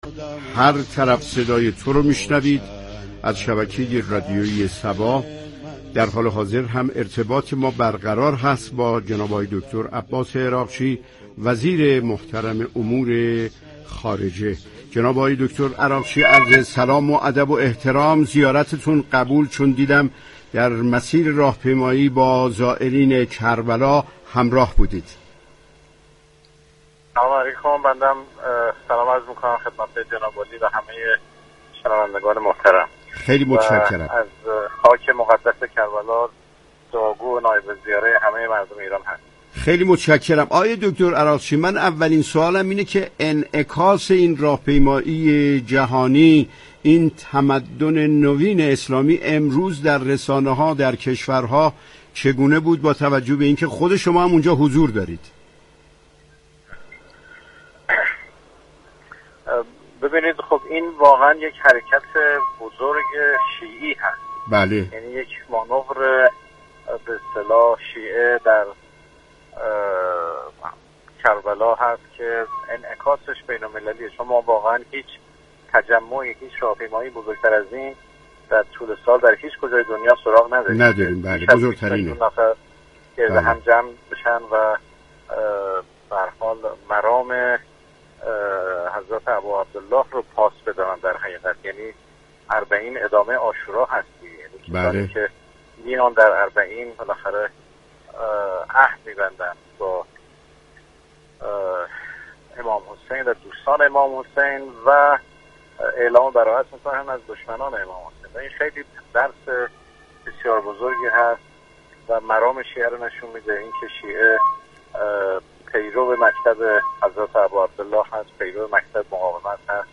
گفتگوی رادیو صبا با دكتر عباس عراقچی: راهپیمایی اربعین نماد همبستگی شیعه در جهان